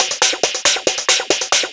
DS 138-BPM B4.wav